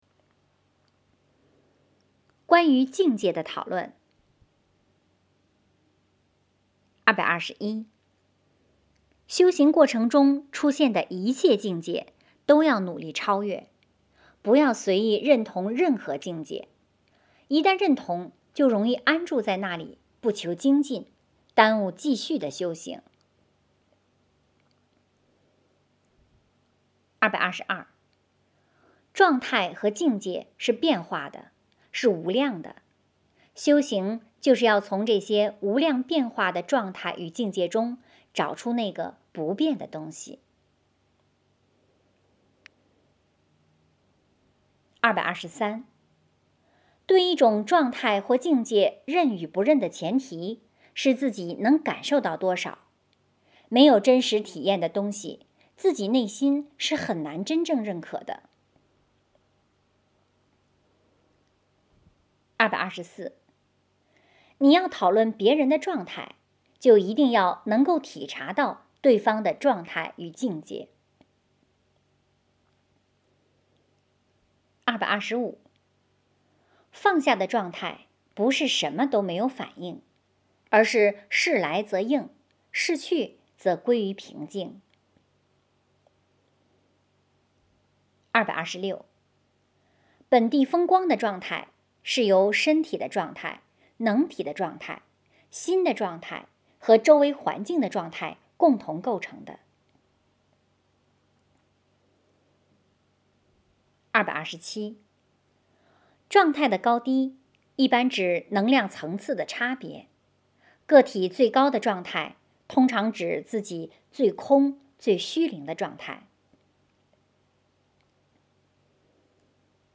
有声读物 - 实修世界